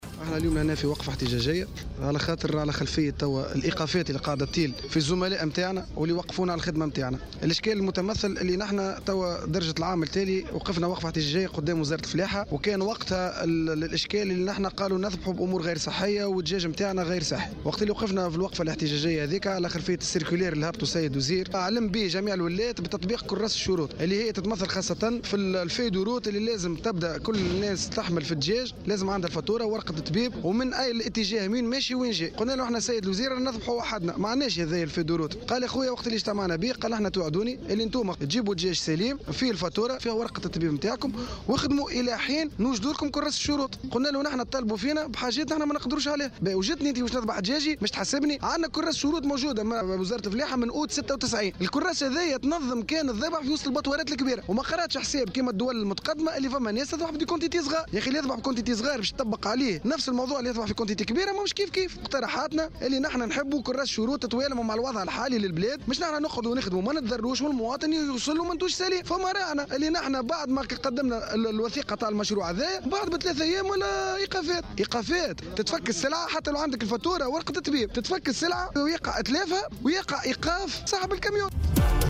في تصريح لمراسلة "الجوهرة أف أم"